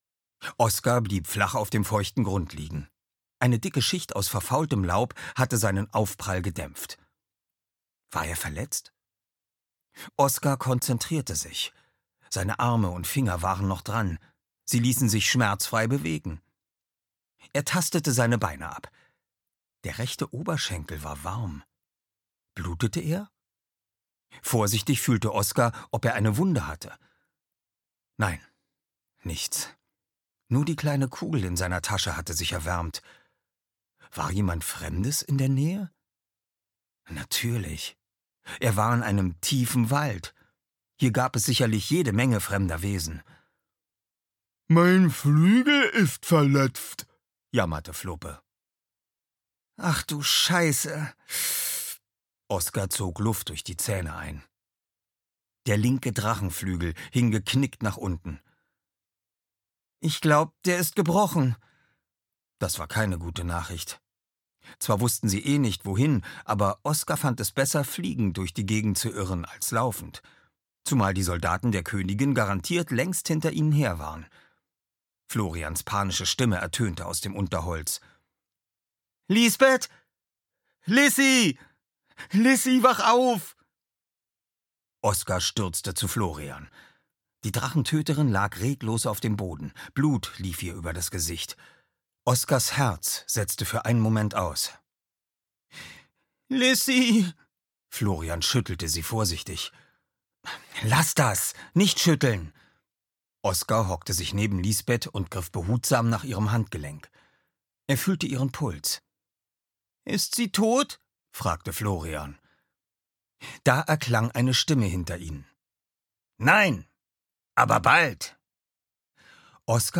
Die Legende von Drachenhöhe 2: Aufbruch der Helden - Frank Schmeißer - Hörbuch